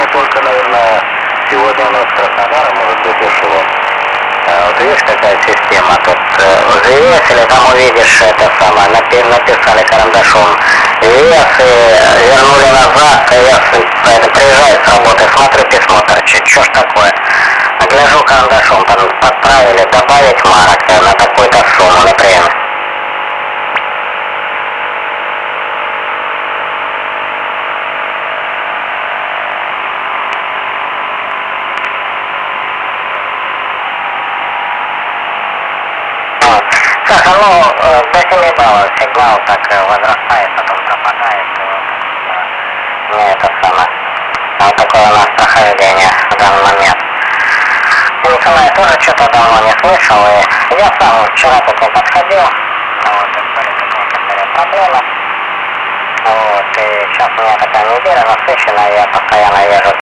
ricetrasmettitore SSB QRP in 20m
I file sono dei piccoli MP3 da uno o due minuti... c'è fonia, cw e rtty...
l'antenna è il dipolaccio ex_ricevitore, in pratica un pezzo di filo steso sul tetto,